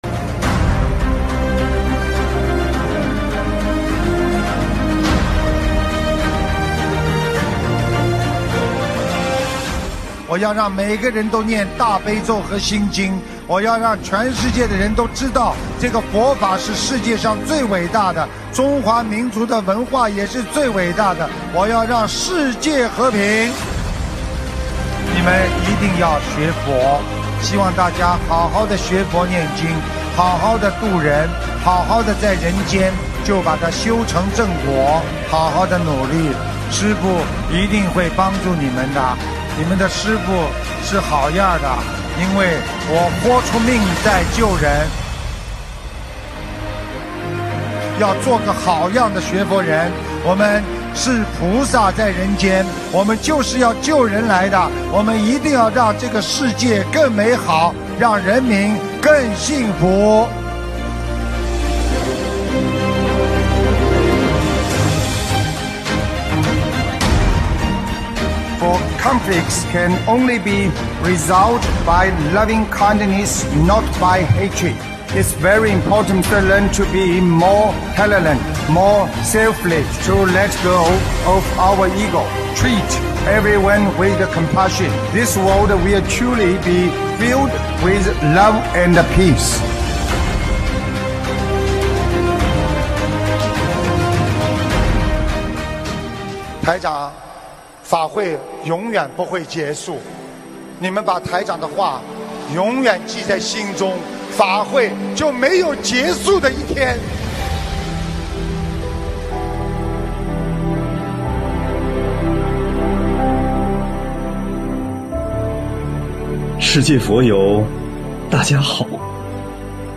音频：缅甸密支那 观音堂开光大典2022年03月10日